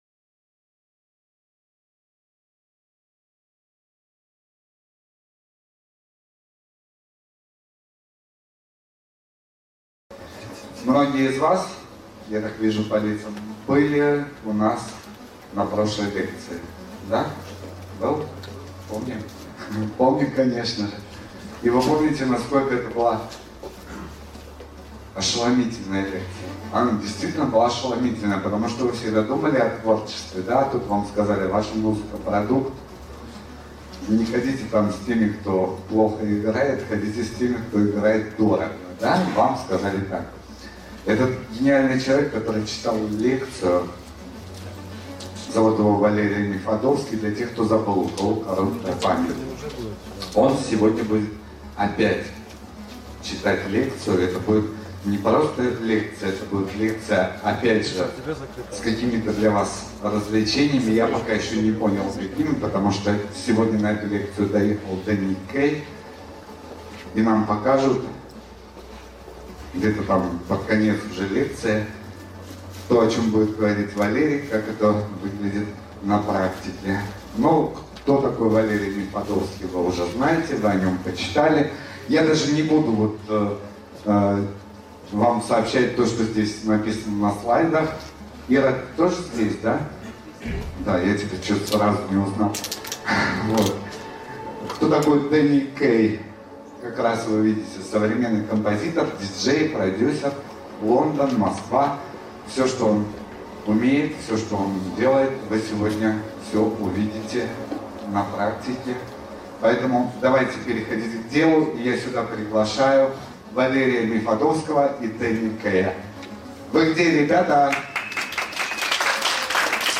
Аудиокнига Музыка.